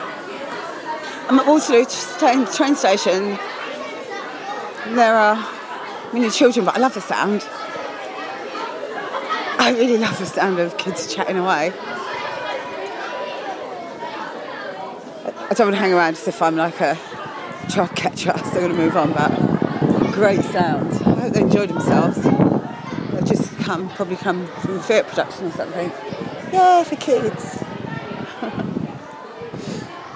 loads of children at Waterloo station